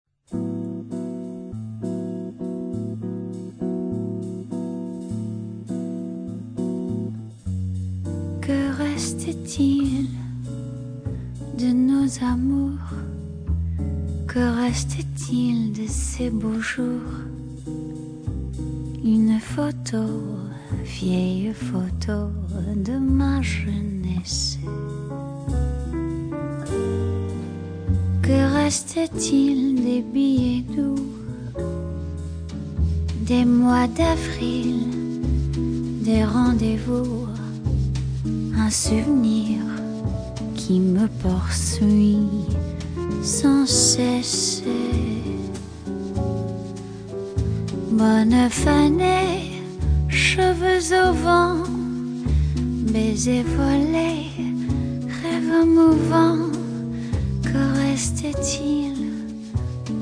au phrasé doux et mélodieux.
dans des versions frêles et épurées
Saxes, backing vocals
Guitar
Piano, keyboards, backing vocals
Bass
Drums